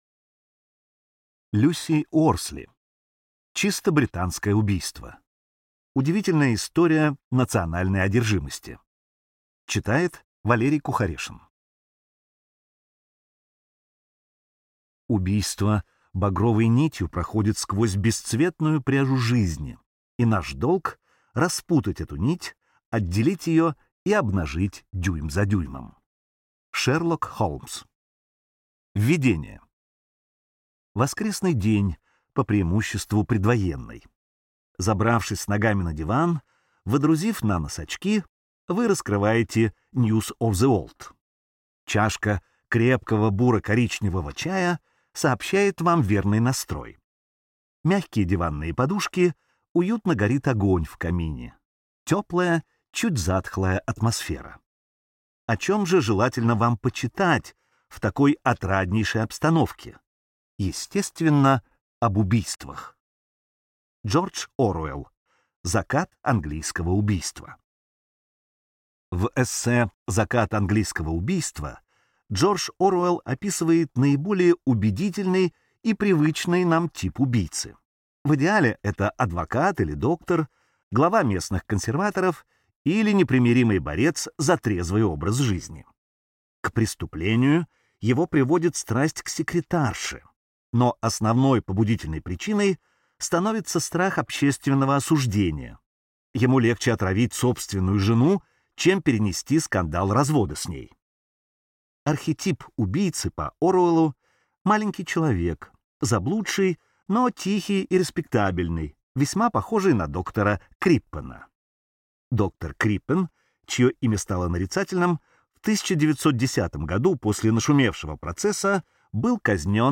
Аудиокнига Чисто британское убийство. Удивительная история национальной одержимости | Библиотека аудиокниг